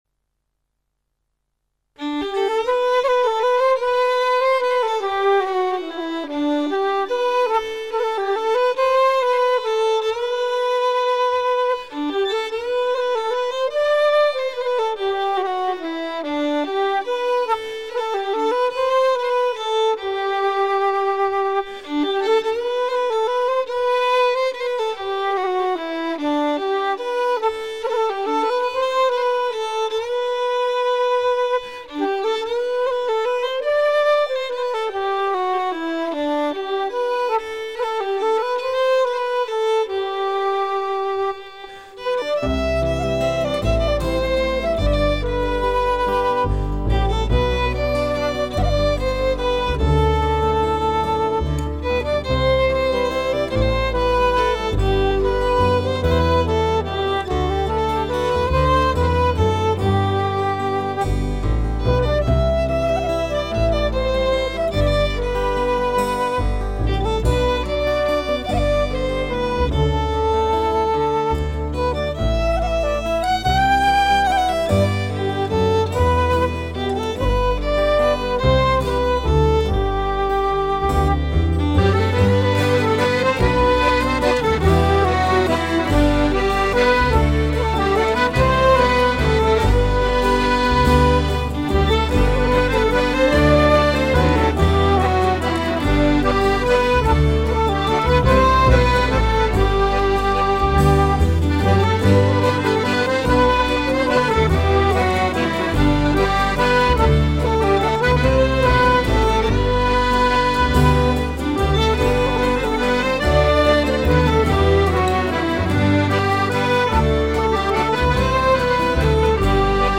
BALSAK